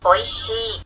o  i  shi  i